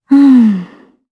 Ripine-Vox_Think_jp.wav